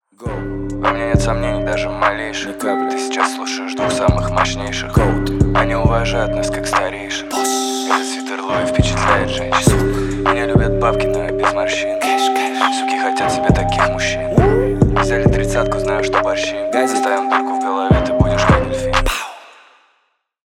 • Качество: 320 kbps, Stereo
тихие